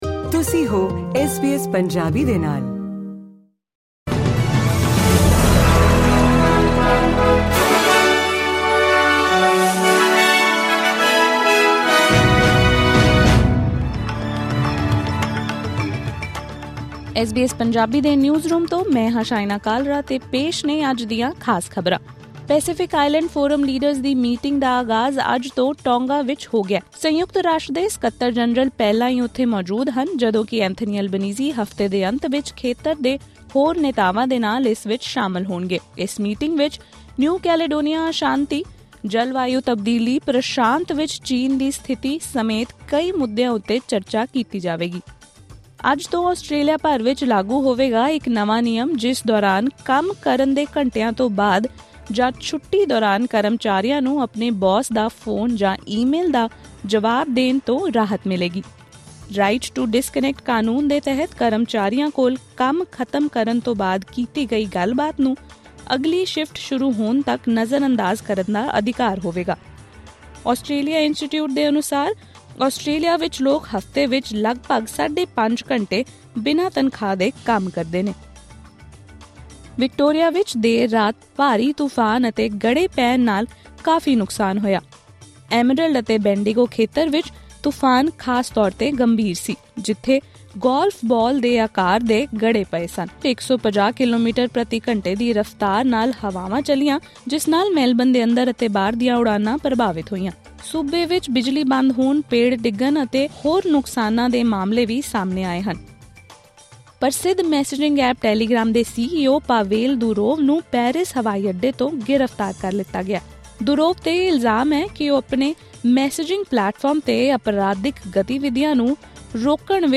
ਐਸ ਬੀ ਐਸ ਪੰਜਾਬੀ ਤੋਂ ਆਸਟ੍ਰੇਲੀਆ ਦੀਆਂ ਮੁੱਖ ਖ਼ਬਰਾਂ: 26 ਅਗਸਤ 2024